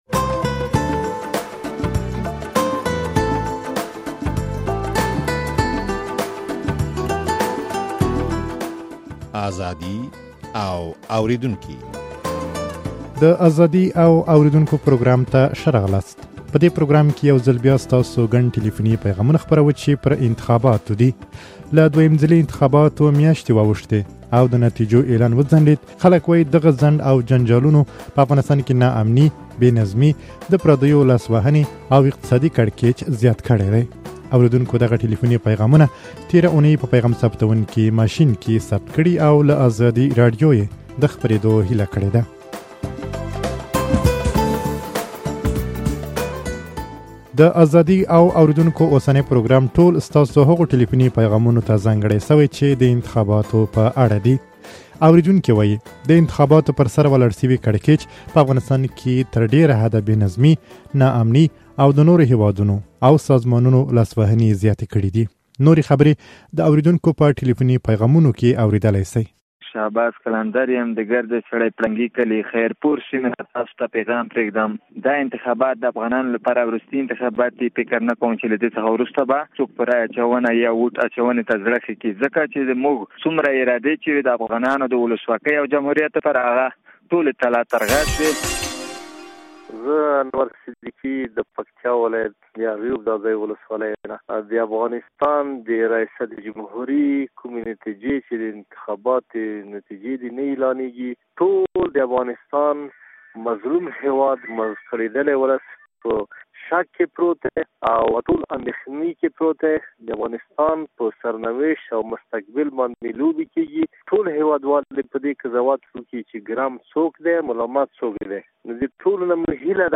په دې پروګرام کې يوځل بيا ستاسو ګڼ ټليفوني پيغامونه خپروو، چې پر انتخاباتو دي.